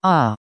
Girl Voice Changer - Botón de Efecto Sonoro